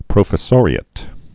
(prōfĭ-sôrē-ət, prŏfĭ-)